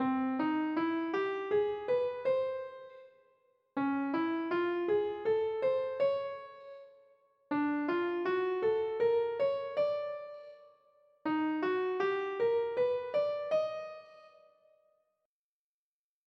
The augmented scale consists of a minor third followed by a half step, repeated.
augmented scale construction
augmented-scale.mp3